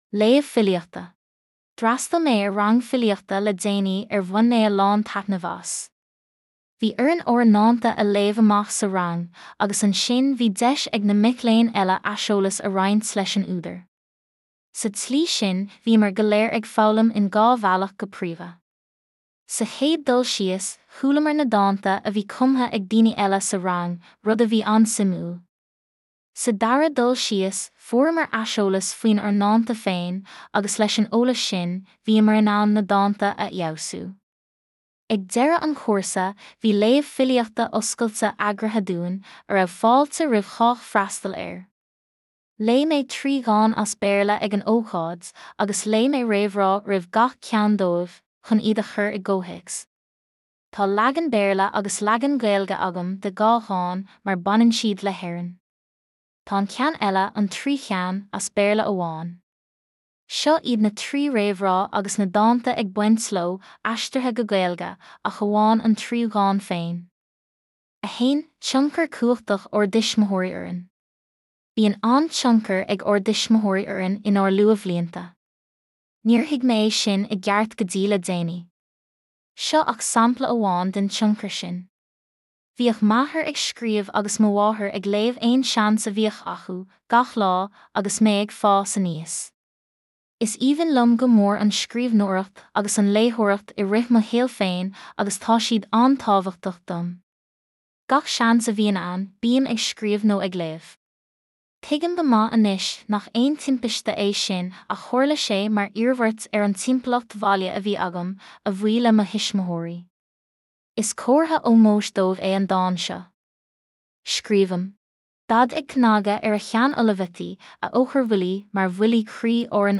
Léamh Filíochta